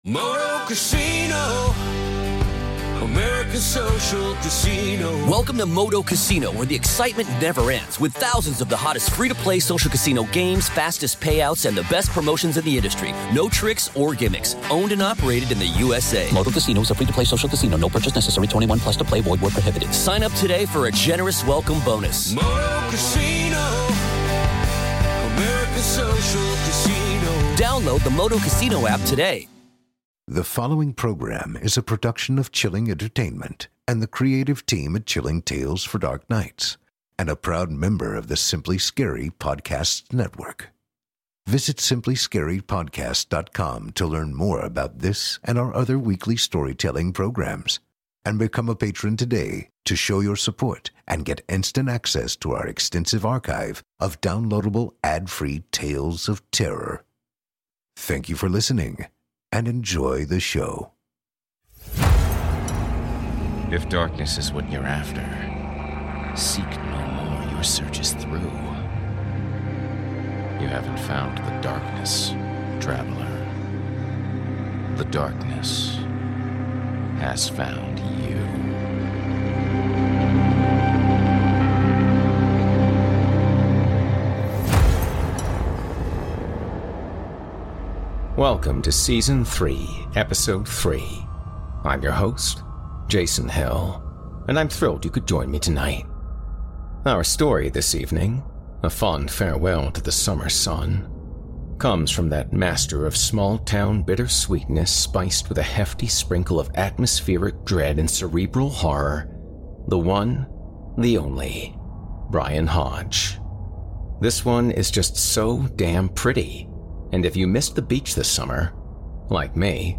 a terrifying feature-length tale